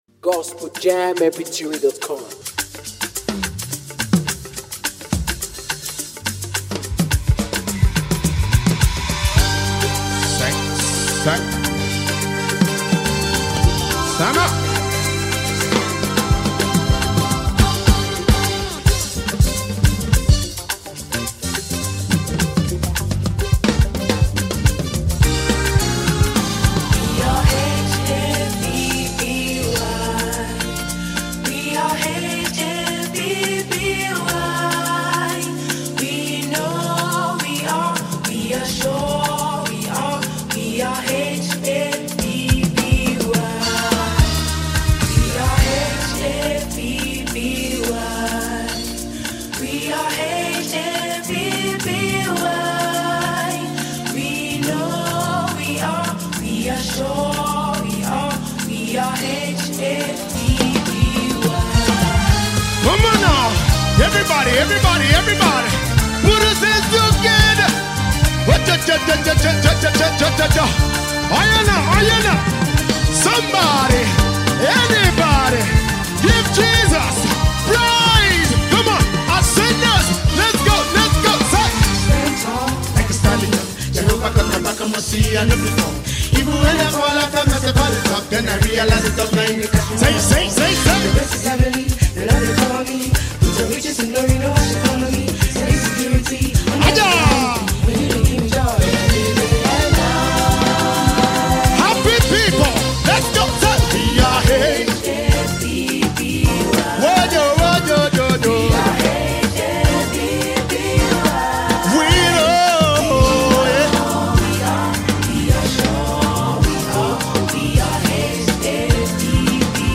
melodious sound